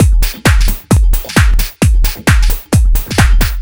132 Skip N Groove Full.wav